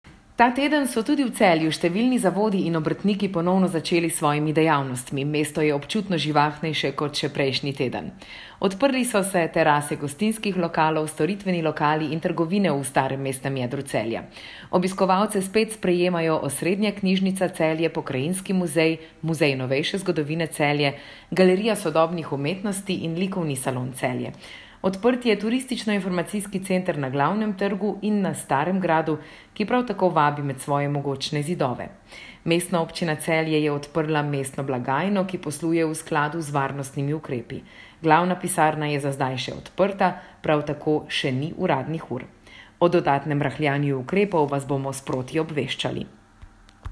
Tonska izjava: